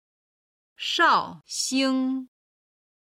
今日の振り返り中国語の音源
绍兴 (shào xīng)